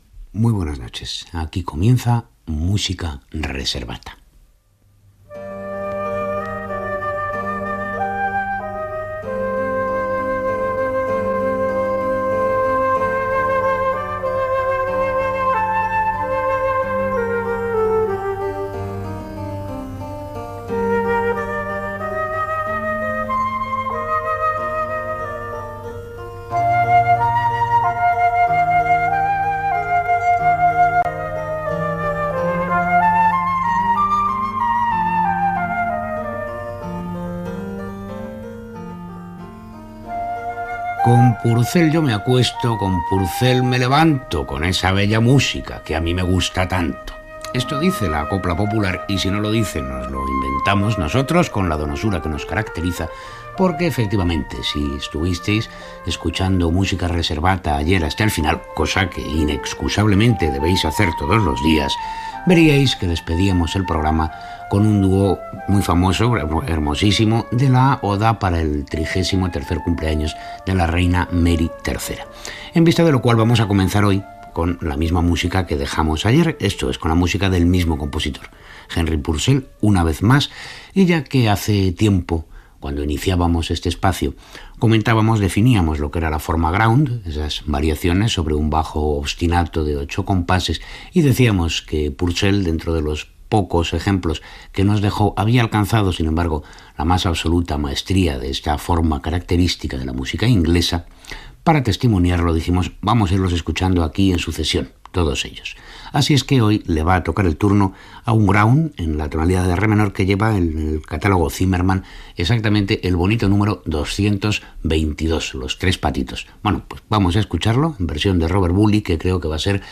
Presentació, sintonia, record al programa del dia anterior i tema musical
Musical
FM